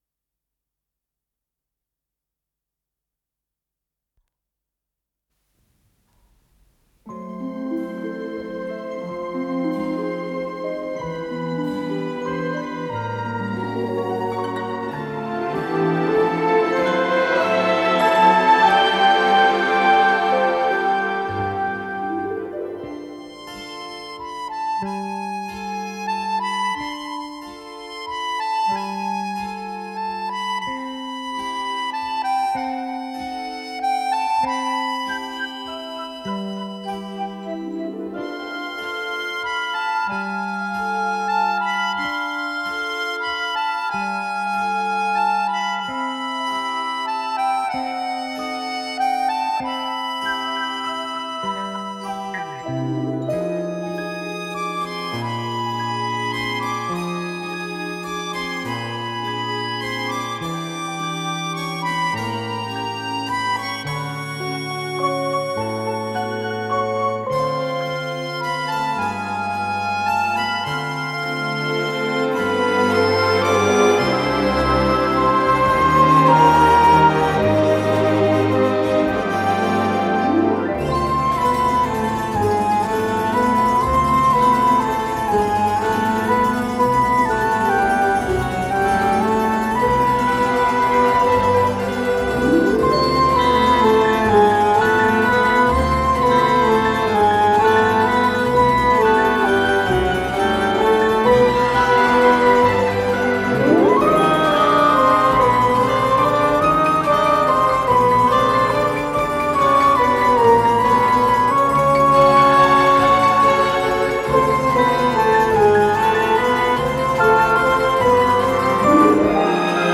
Скорость ленты38 см/с
ВариантДубль моно